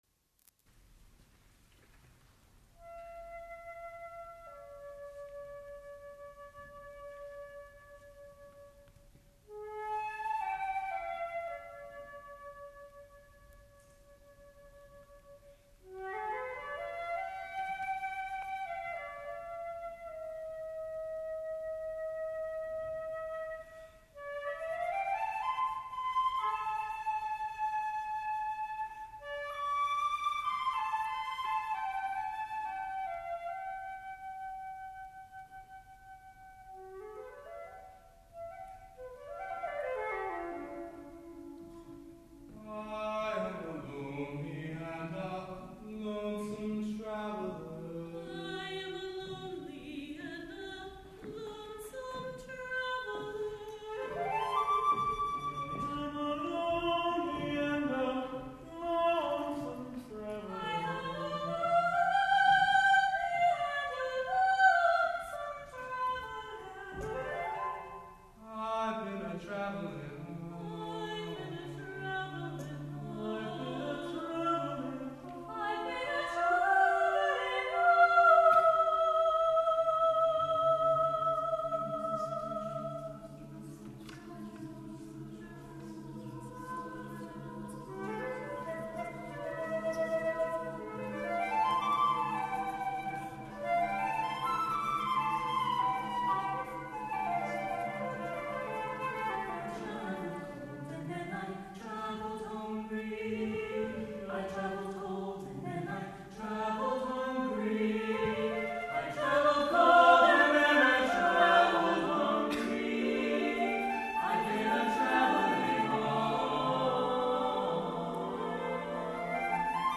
for SATB Chorus and Flute (1992)